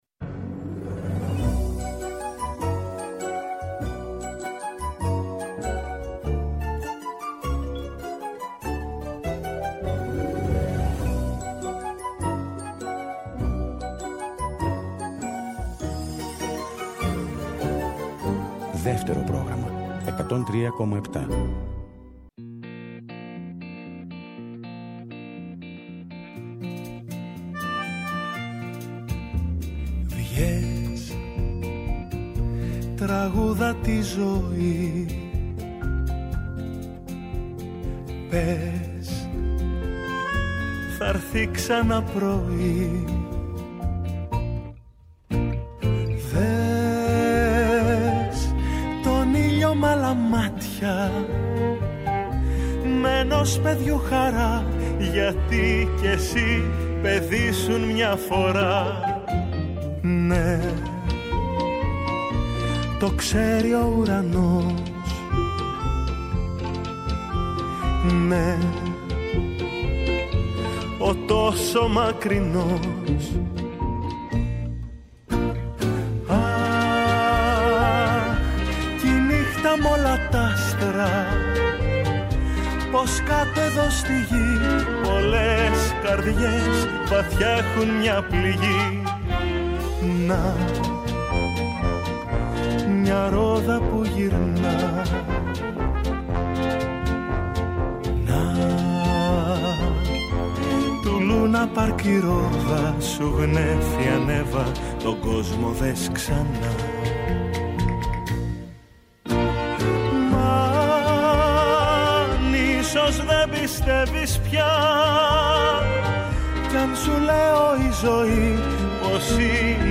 «Άλλη μια μέρα» στον αέρα του Δεύτερου, εκπομπή καλής διάθεσης και μουσικής, για την ώρα που η μέρα φεύγει και η ένταση της μέρας αναζητά την ξεκούραση και τη χαρά της παρέας.